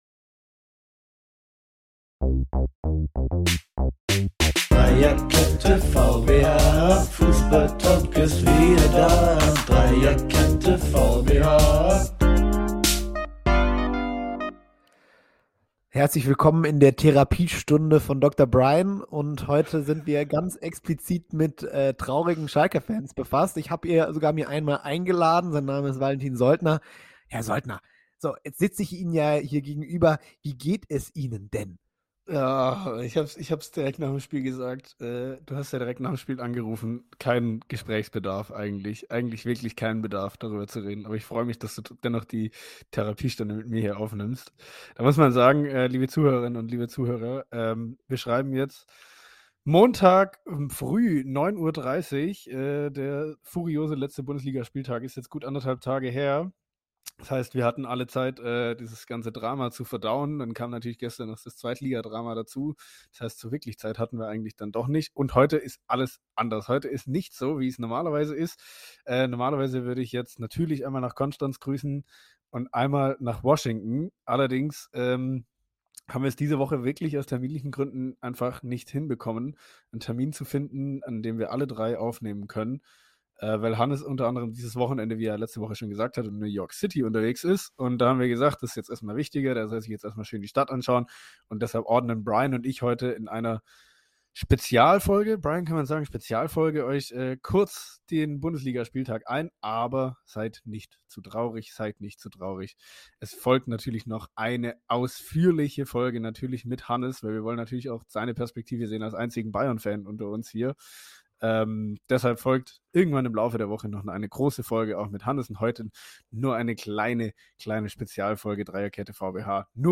Leider diese Woche nur zu zweit...